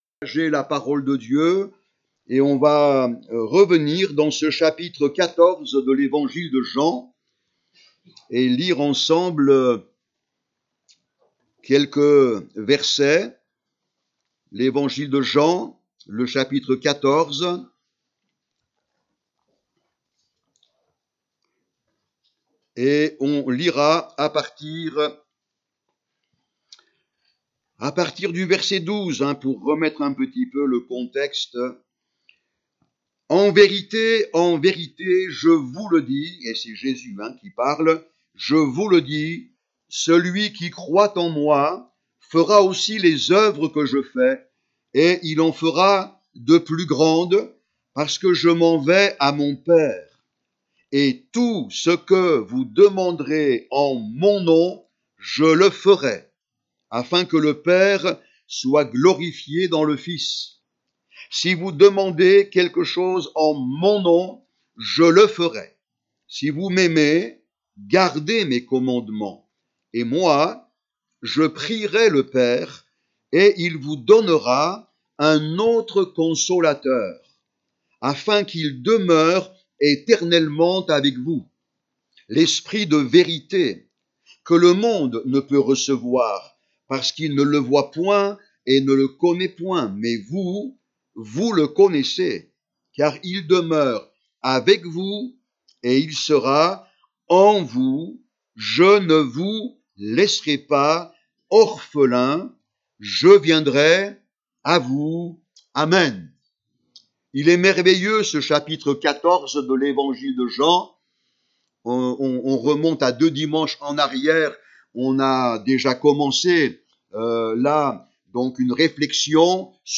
30 janvier 2024 Le Saint Esprit cet autre consolateur Prédicateur